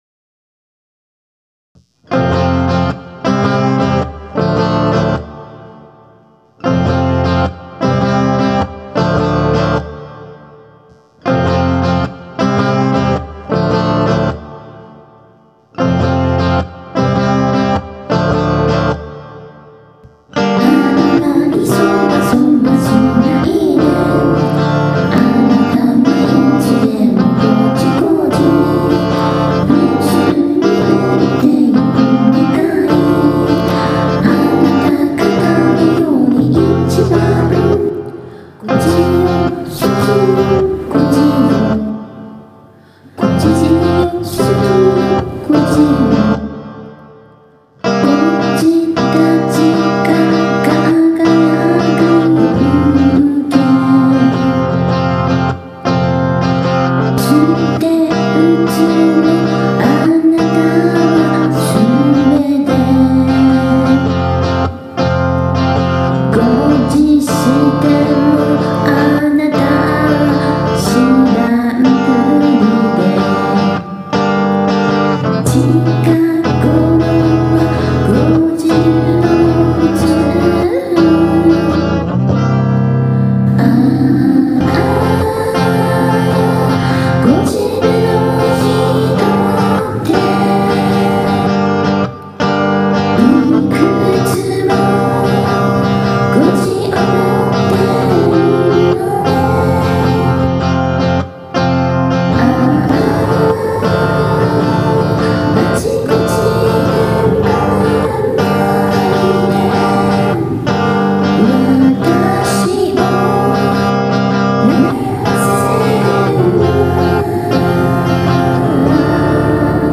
え～。。今回は、あの名曲（？）を詞を変えてアコースティックでｗ
彼女の録音環境がMacの内臓マイクでの歌録り為、
ボーカル部に生活ノイズが入ってるのはご了承くださいｗ